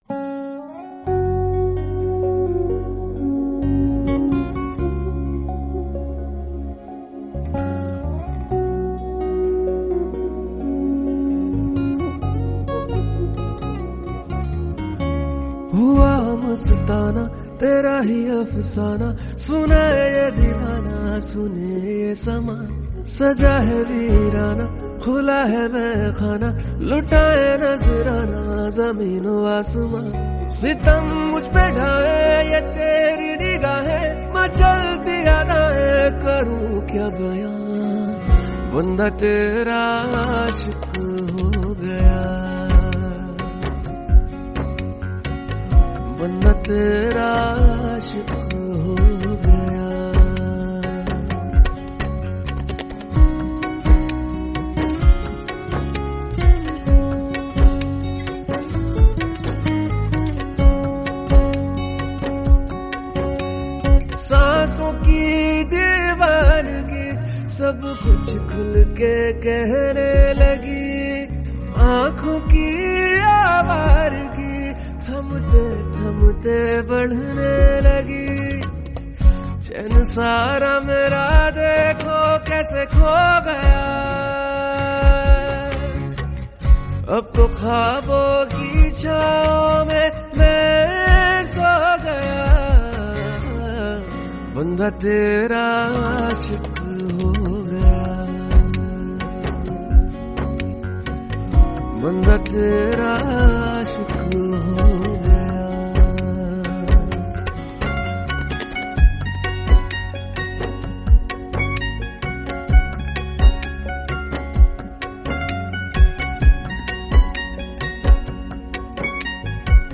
Indian Desi Beats